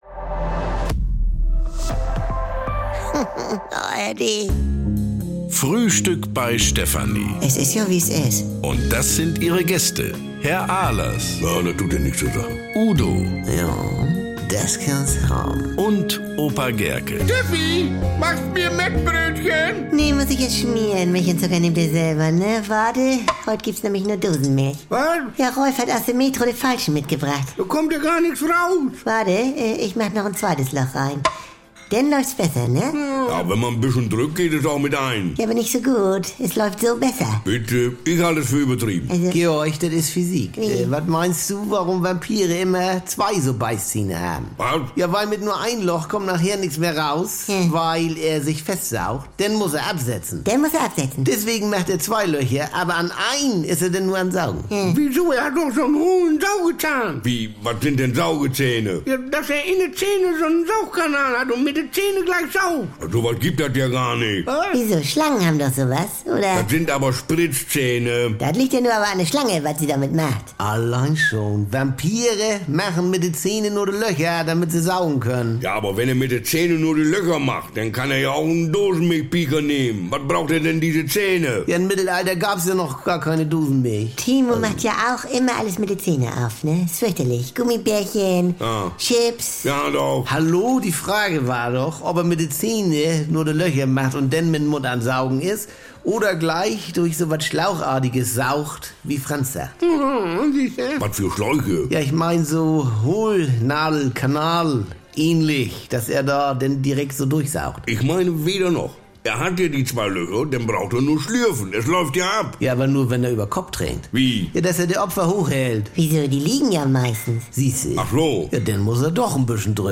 Garantiert norddeutsch mit trockenen Kommentaren, deftigem Humor und leckeren Missverständnissen.